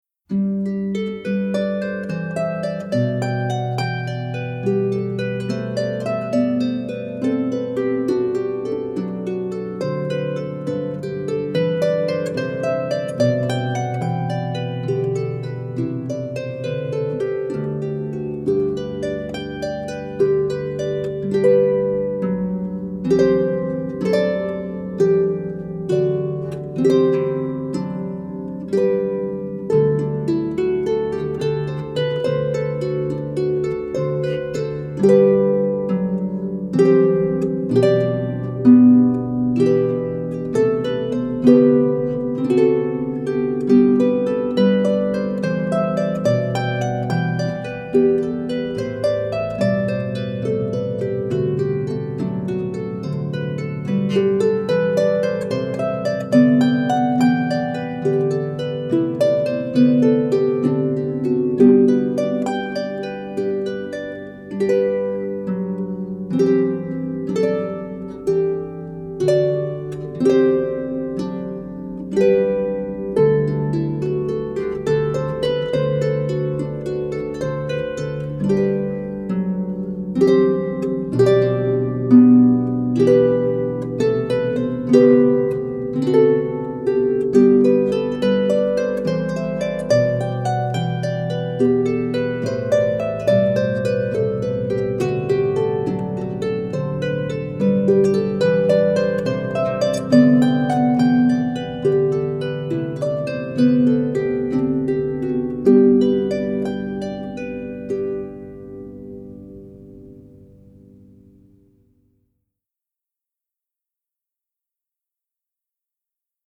solo lever or pedal harp